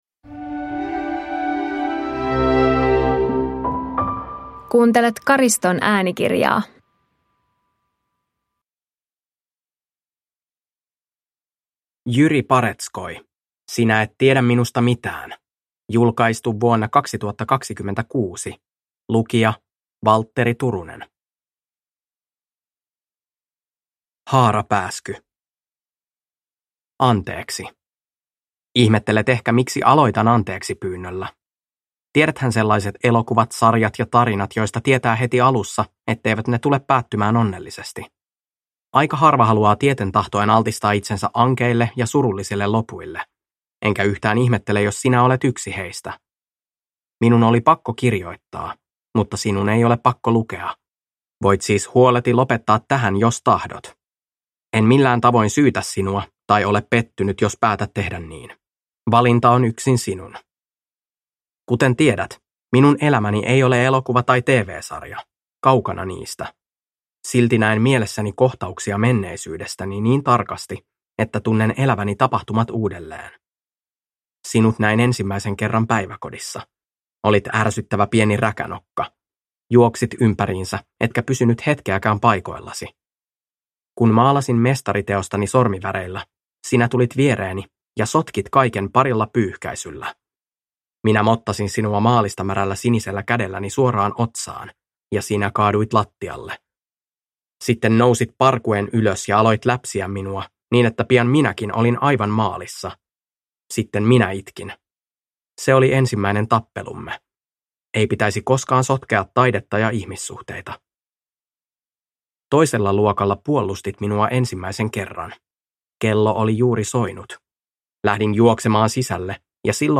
Sinä et tiedä minusta mitään (ljudbok) av Jyri Paretskoi